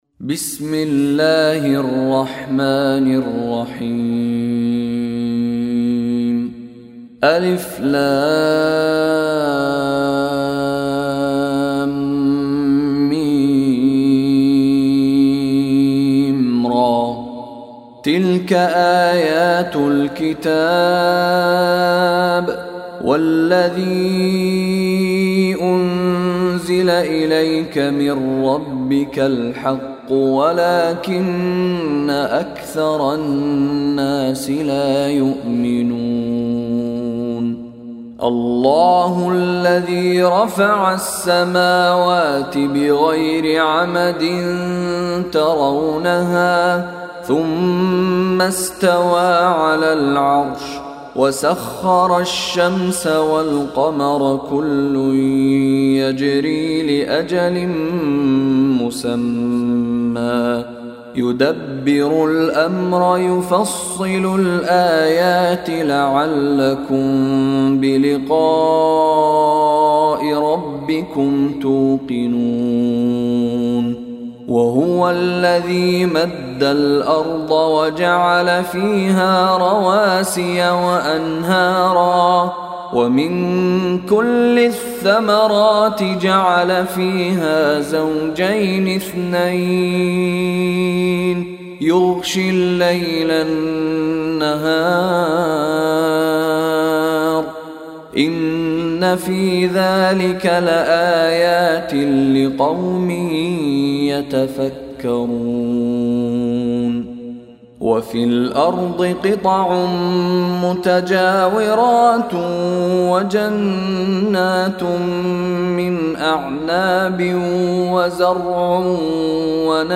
Surah Ar Rad MP3 Download By Sheikh Mishary Rashid Alafasy. Surah Ar Ra'd Beautiful Recitation MP3 Download By Sheikh Mishary Rashid in best audio quality.